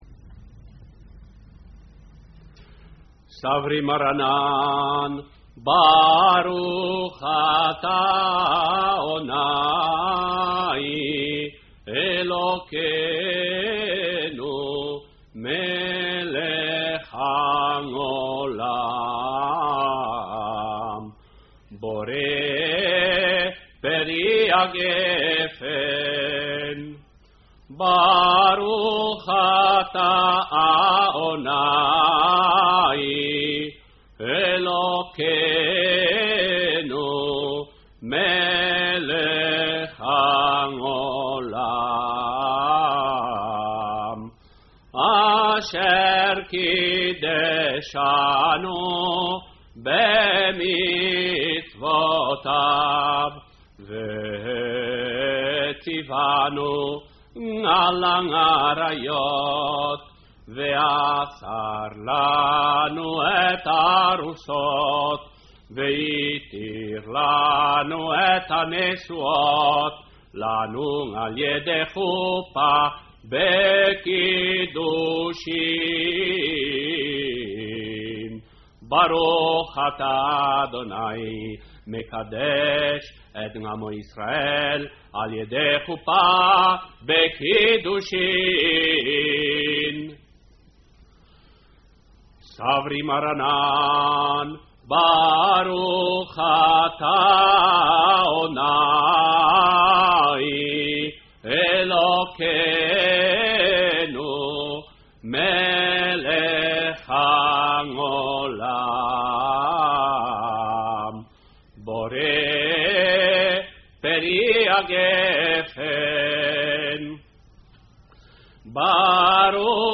rito sefardita tirrenico